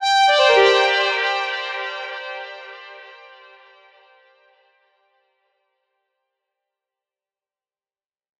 Echoes_G_01.wav